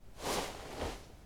Backpack Sounds
action_open_inventory_3.ogg